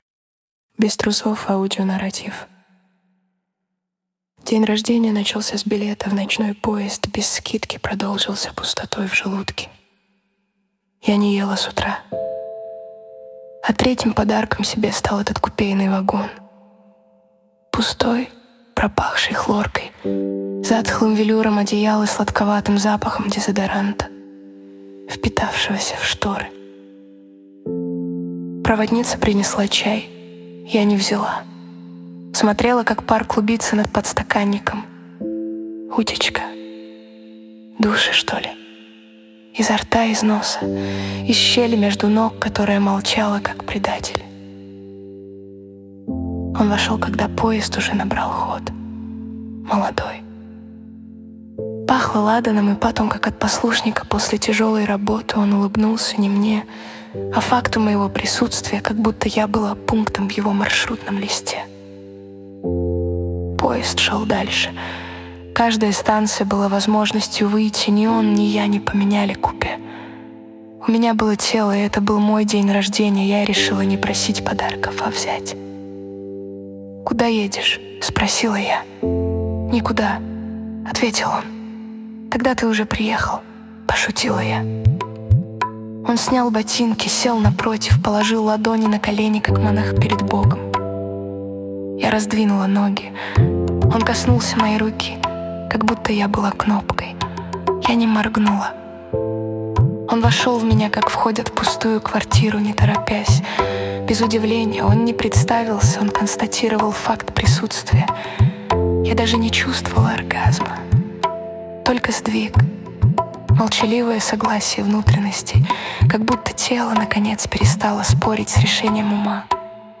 Аудио-нарратив